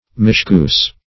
Search Result for " mischoose" : The Collaborative International Dictionary of English v.0.48: Mischoose \Mis*choose"\, v. t. [imp.